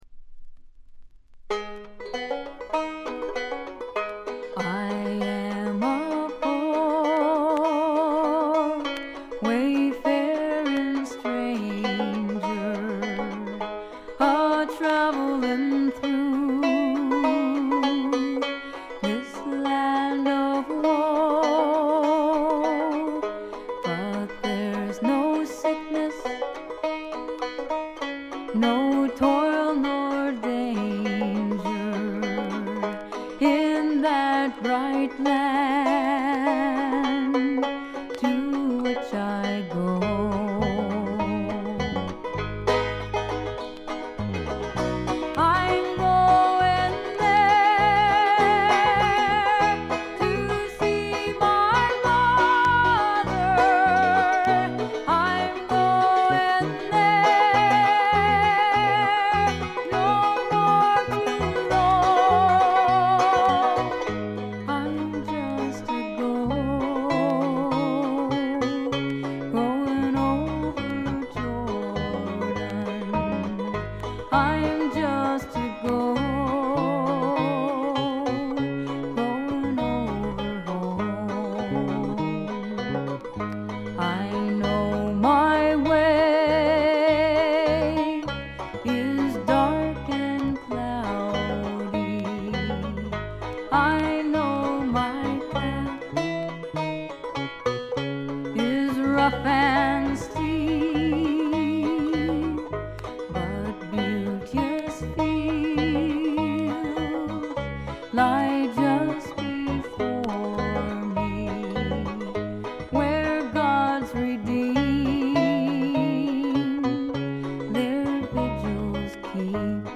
バックグラウンドノイズ、チリプチは大きめで半分ほどでほぼ常時出ます。
アラスカ産の自主制作ヒッピー・フォーク。
音の方は男3女2の構成のオーソドックスなフォークです。
リードヴォーカルはほとんどが美しいフィメールなので、普通にフィメールフォーク作品として聴いていただけます。
いかにもアラスカらしい清澄な空気感と美しい女声ヴォーカルの妙をお楽しみください。
試聴曲は現品からの取り込み音源です。